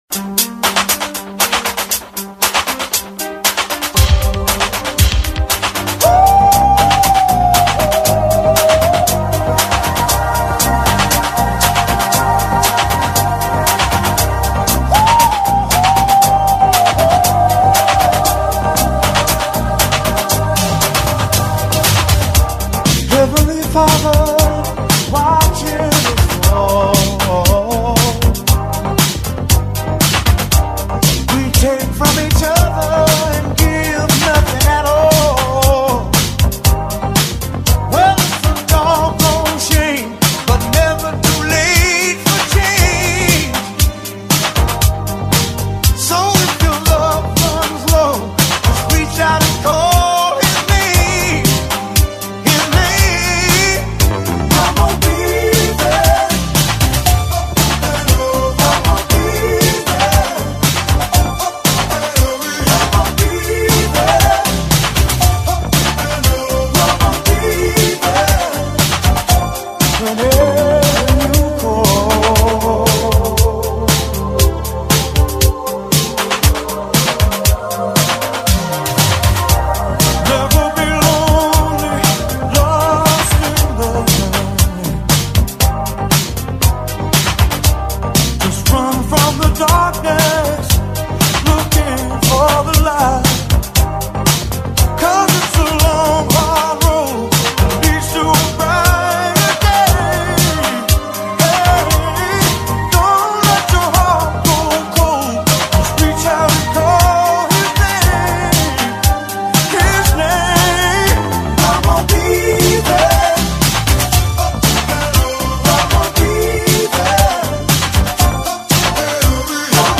Best R&B Performance by a Duo or Group with Vocal